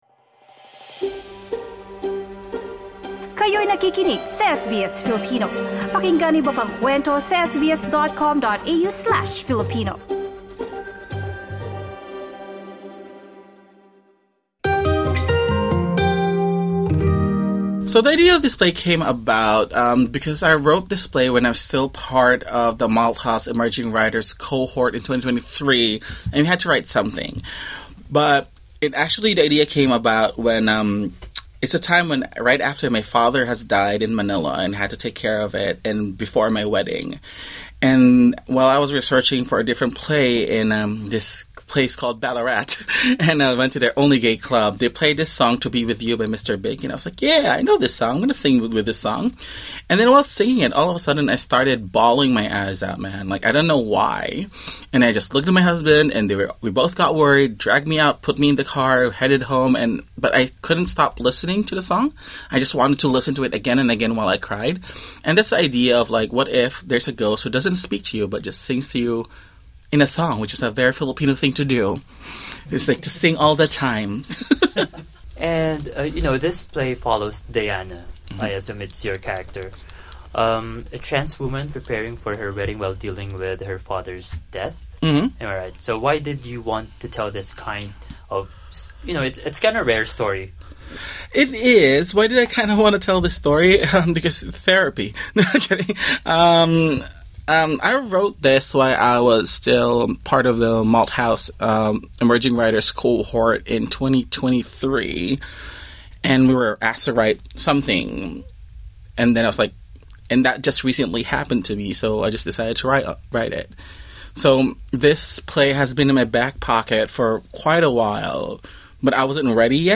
SBS Filipino interviewed the characters